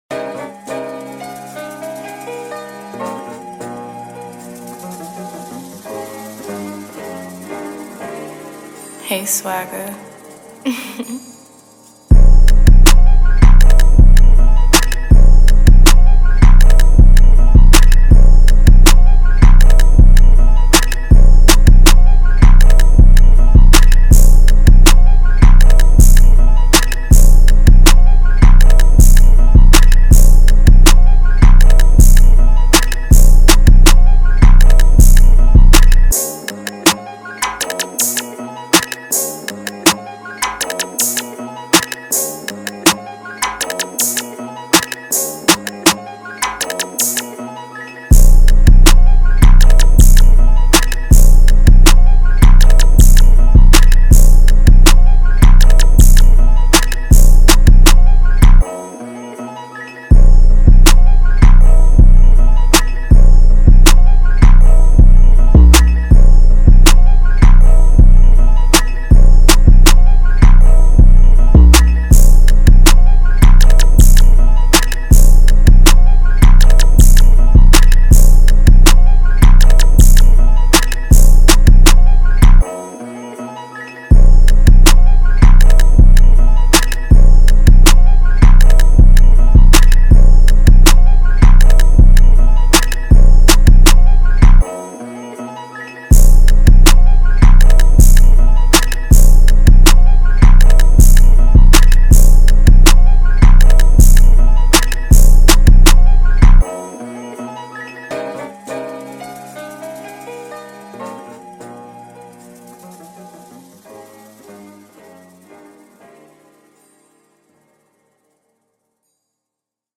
Here's the official instrumental of